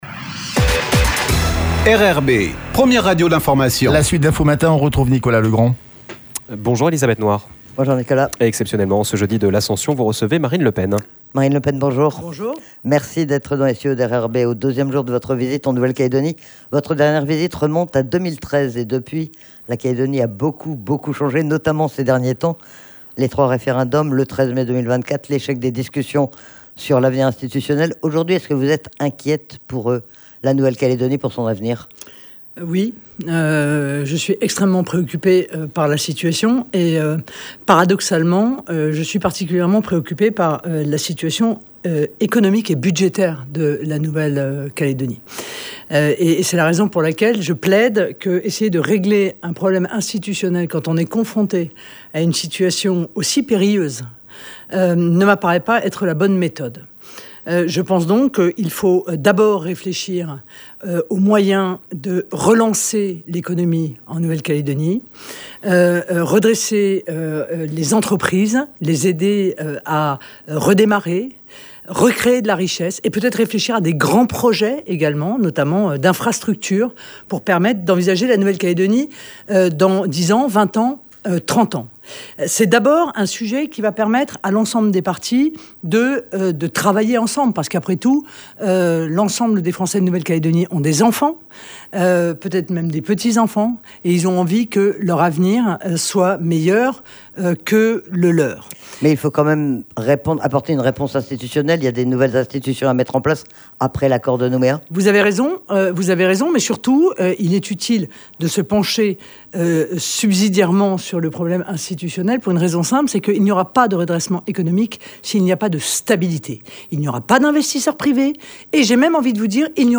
L'INVITÉE DU MATIN : MARINE LE PEN
Marine Le Pen était l’invitée exceptionnelle de RRB.